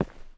mining sounds
ROCK.8.wav